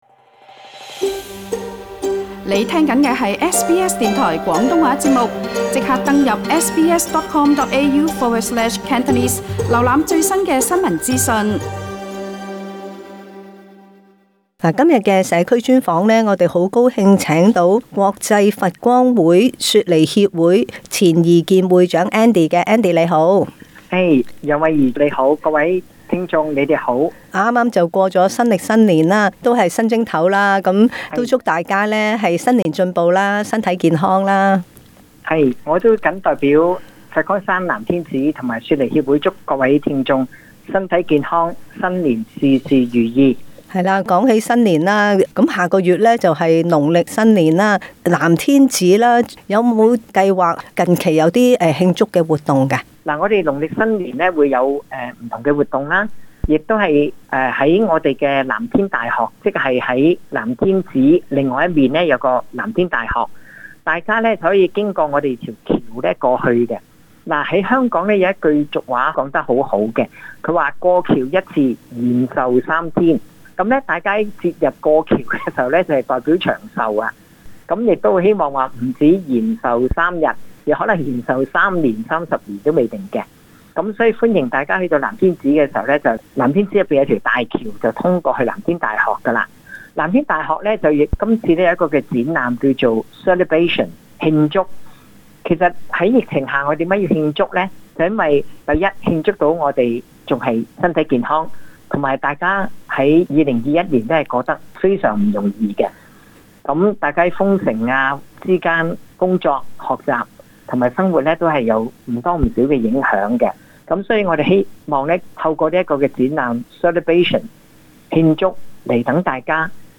【社區專訪】